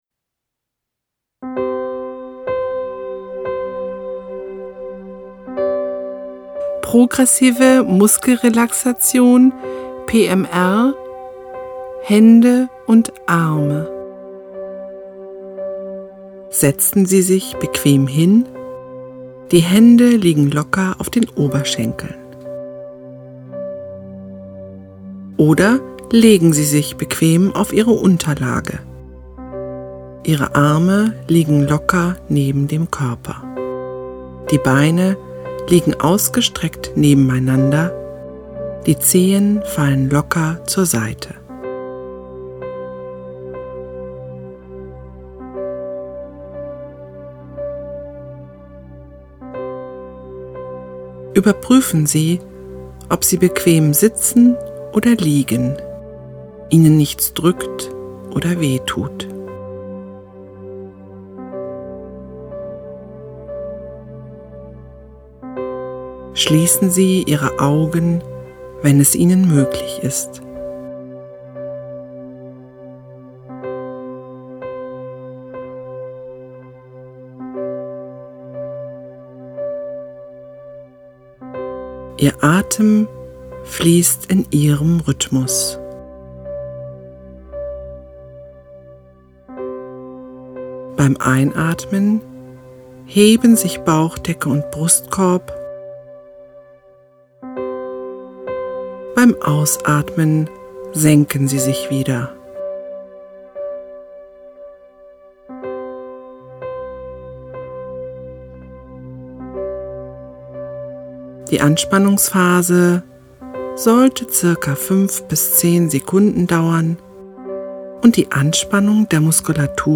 Übung Körperregion: PMR – Hände und Arme